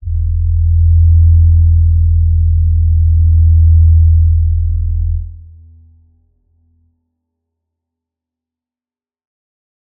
G_Crystal-E2-f.wav